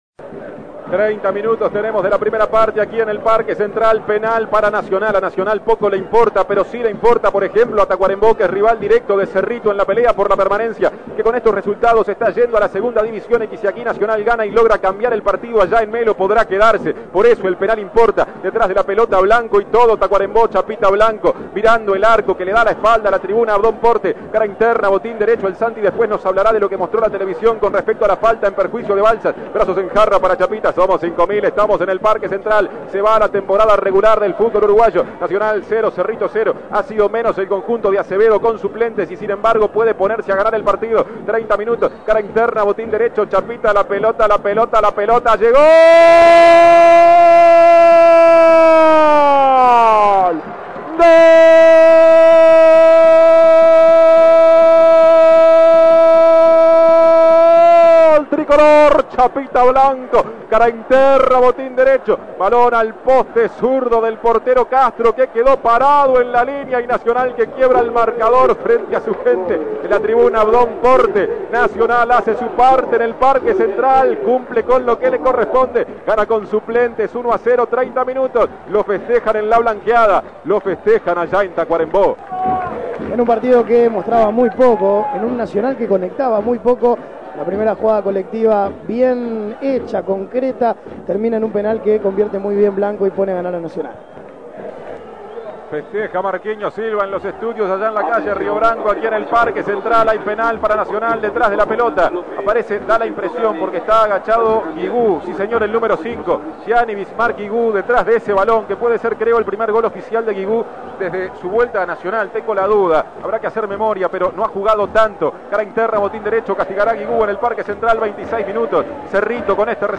Escuche los goles del partido Nacional - Cerrito
Goles y comentarios Escuche los goles del partido Nacional - Cerrito Imprimir A- A A+ Nacional le ganó a Cerrito 3-1 por la 15ª fecha del Torneo Clausura.